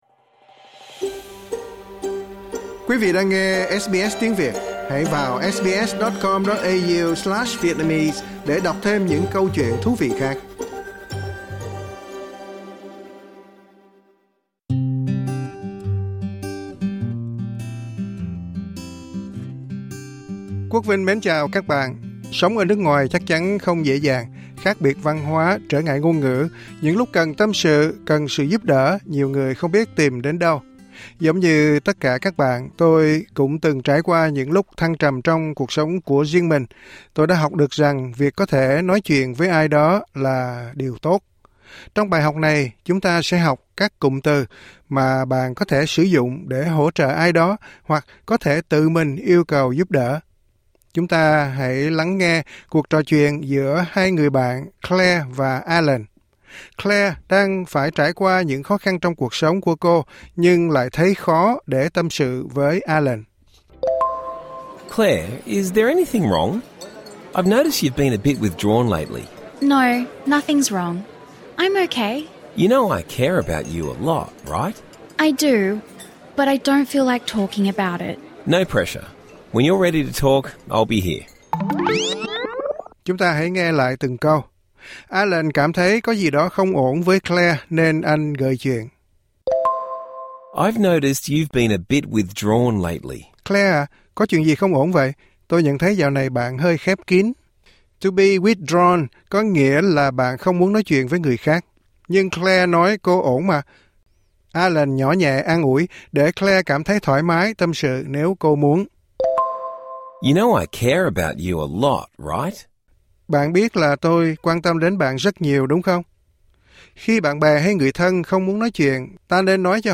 Trong bài học tiếng Anh kỳ này, chúng ta sẽ học các cụm từ mà bạn có thể sử dụng để hỗ trợ ai đó hoặc có thể tự mình yêu cầu giúp đỡ.